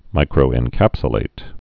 (mīkrō-ĕn-kăpsə-lāt)